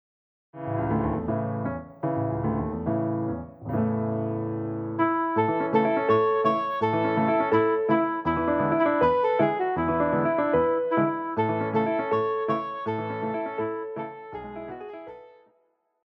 Classical
Vocal - female,Vocal - male
Piano
Voice with accompaniment
This is a lively piece written in Modified Strophic Form.
This arrangement is in A major.